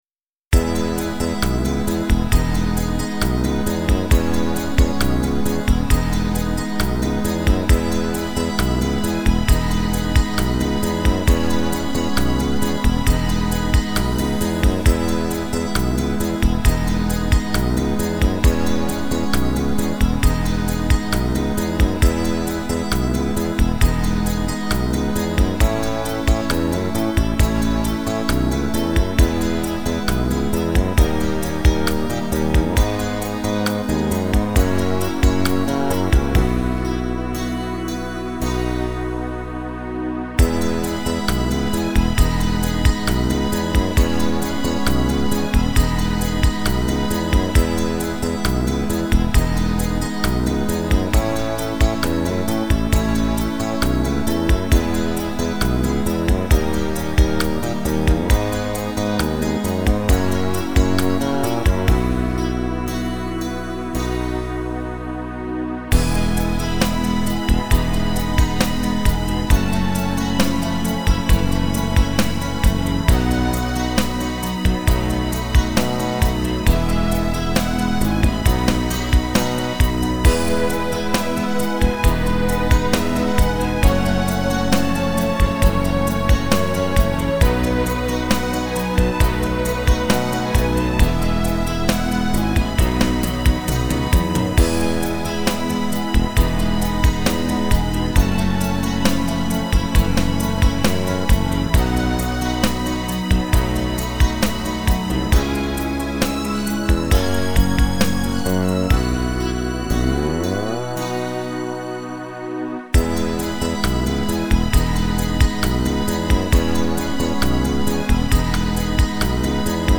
минусовка версия 174529